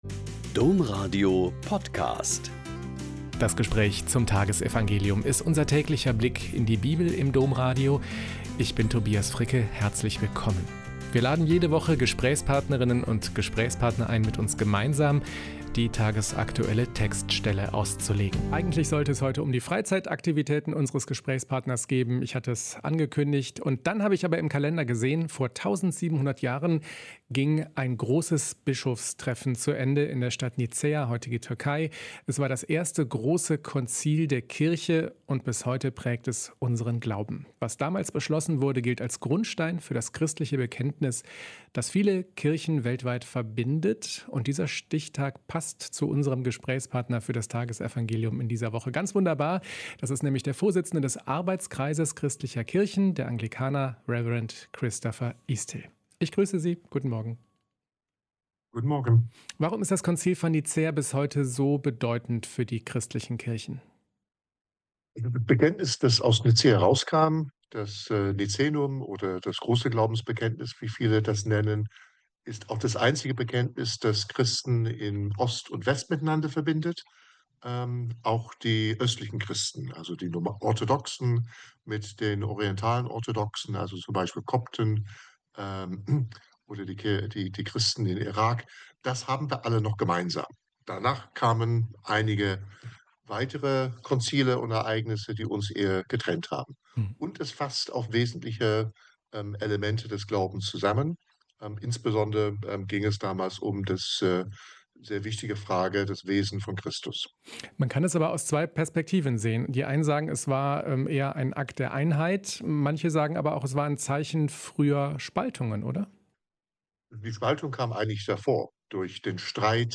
Mt 20,20-28 - Gespräch